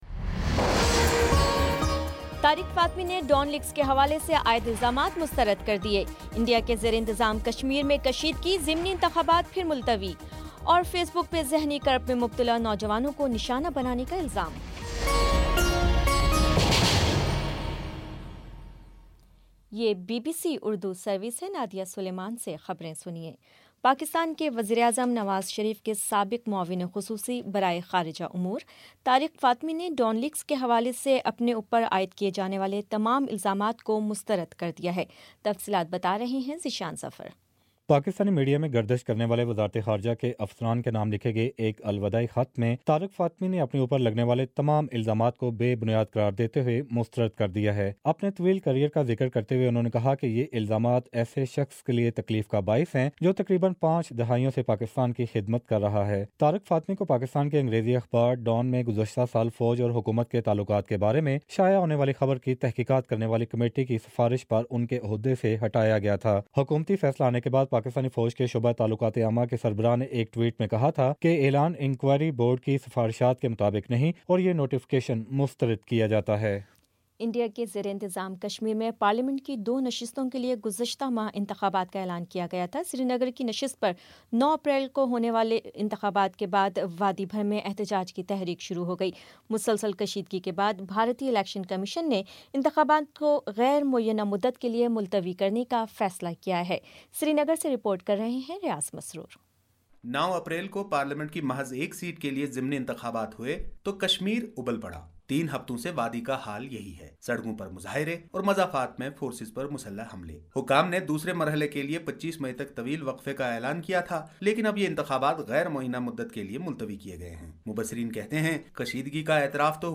مئی 02 : شام چھ بجے کا نیوز بُلیٹن